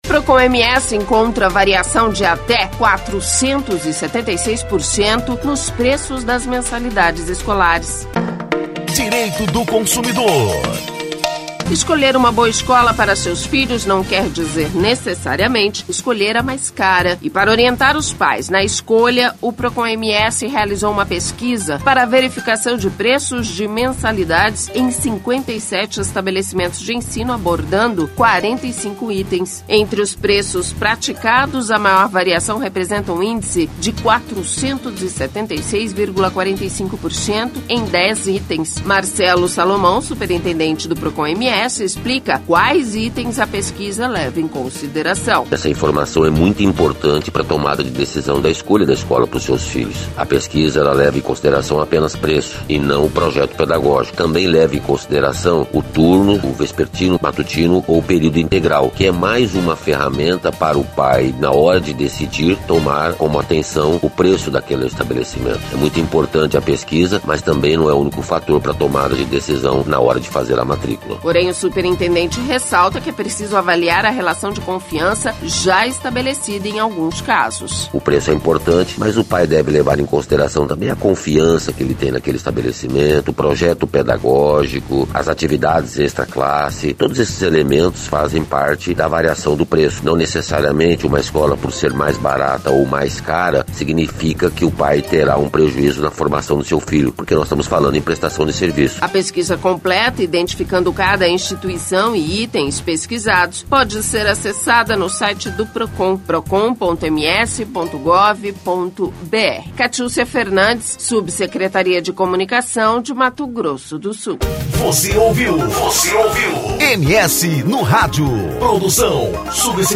Marcelo Salomão, superintendente do Procon/MS explica quais itens a pesquisa leva em consideração.
Porém, o superintendente ressalta que é preciso avaliar a relação de confiança já estabelecida em alguns casos.